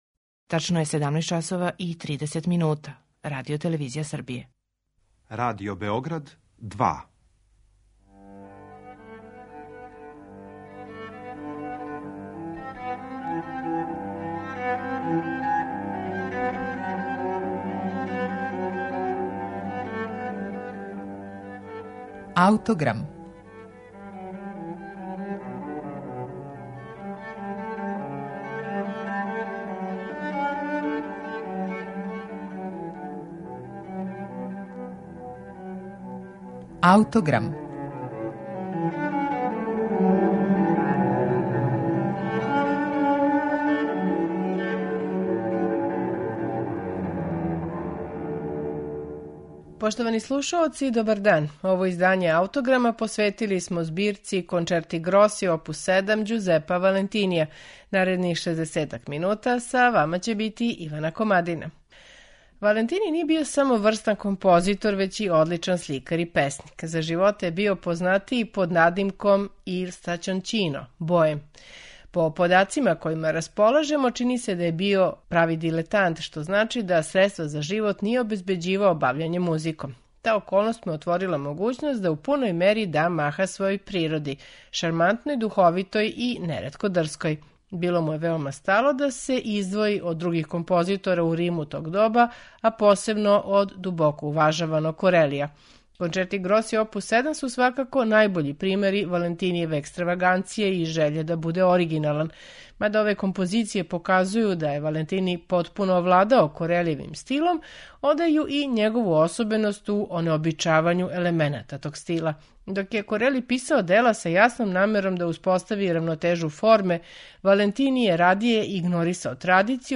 У данашњем АУТОГРАМУ слушамо Четири концерта из Валентинијеве збирке Concerti grossi опус 7